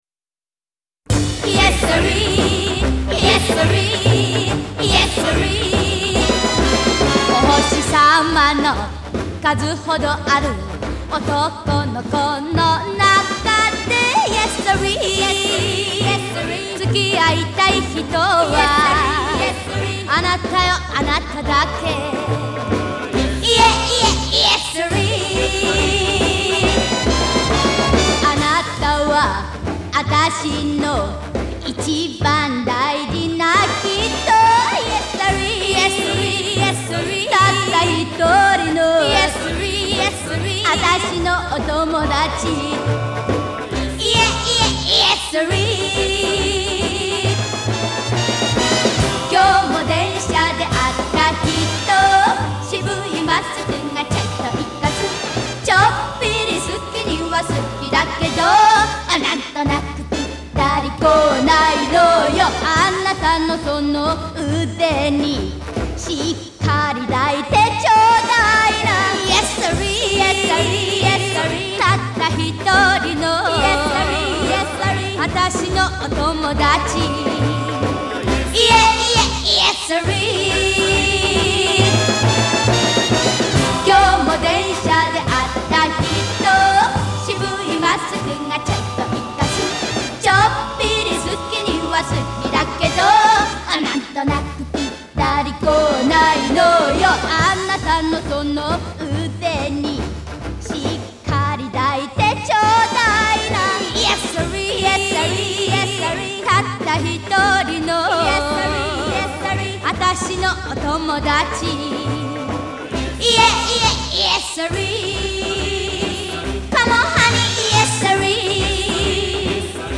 Жанр: Jpop,Retro